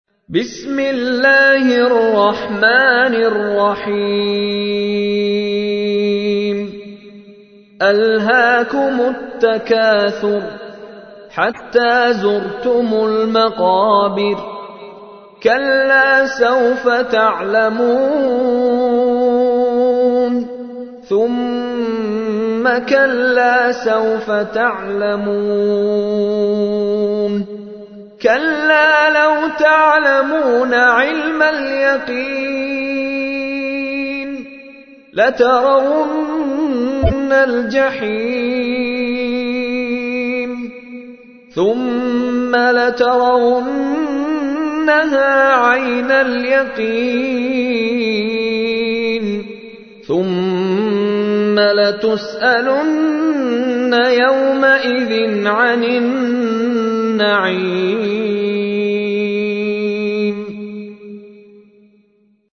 تحميل : 102. سورة التكاثر / القارئ مشاري راشد العفاسي / القرآن الكريم / موقع يا حسين